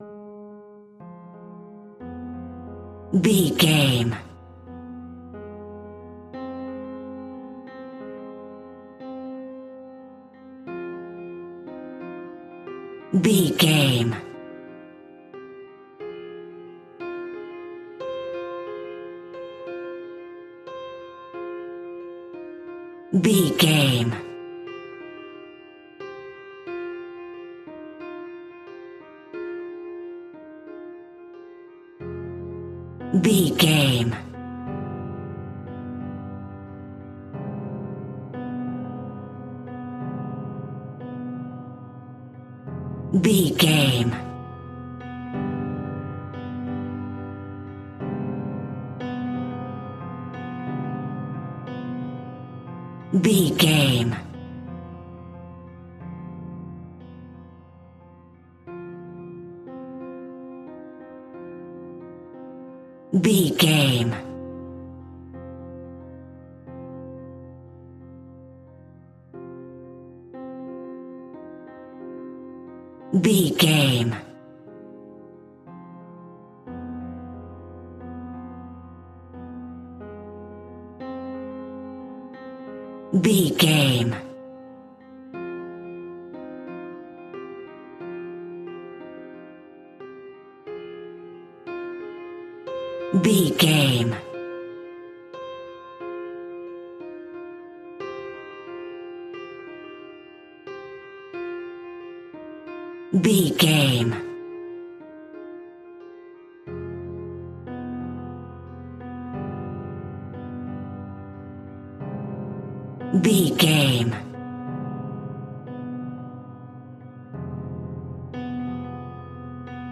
Nightmare on the Piano.
Aeolian/Minor
WHAT’S THE TEMPO OF THE CLIP?
scary
ominous
haunting
eerie
horror music
horror piano